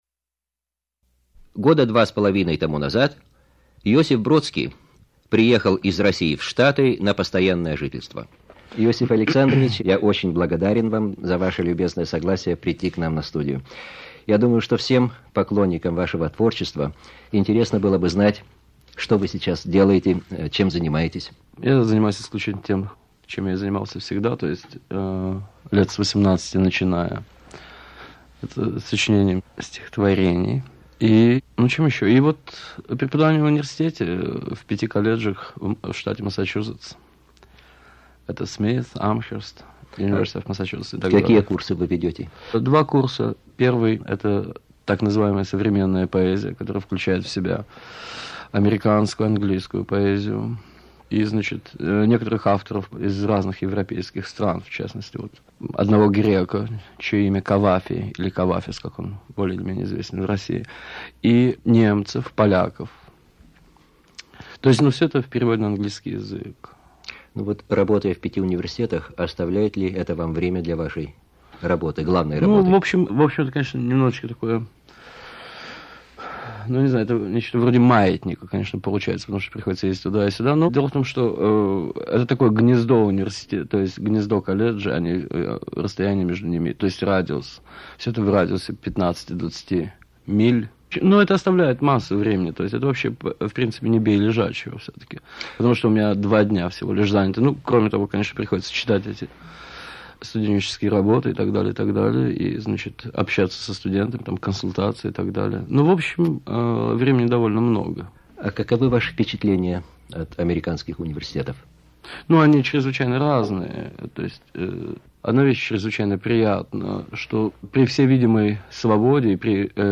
Радиоинтервью с поэтом Иосифом Бродским
Одно из первых больших радиоинтервью русского поэта Иосифа Александровича Бродского в эмиграции.
Радиостудия Русской службы "Голоса Америки".